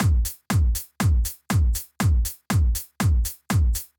Drumloop 120bpm 06-C.wav